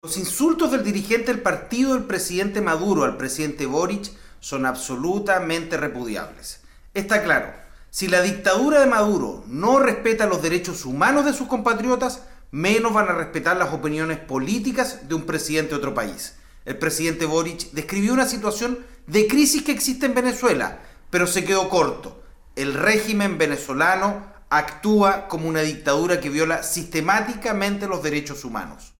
AUDIO : DIPUTADO MANOUCHEHRI